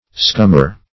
Scummer \Scum"mer\, v. i.